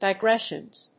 /daɪˈgrɛʃʌnz(米国英語), daɪˈgreʃʌnz(英国英語)/